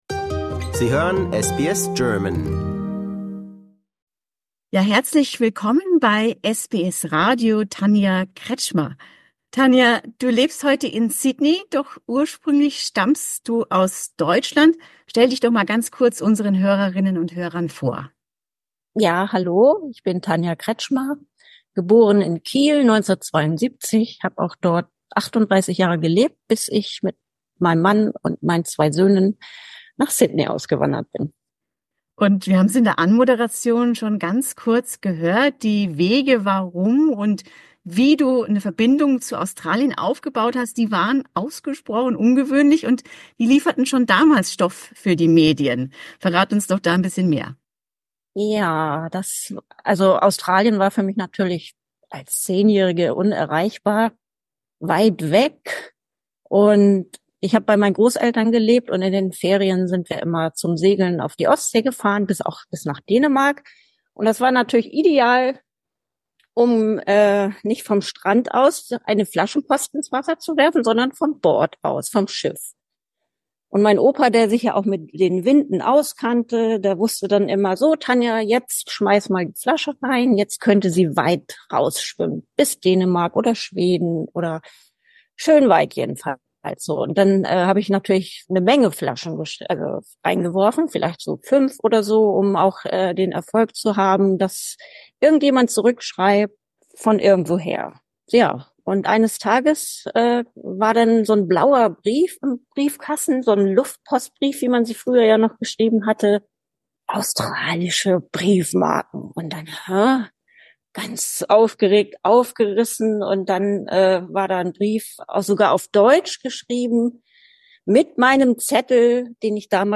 In the interview, she remembers this extraordinary story and shares her impressions.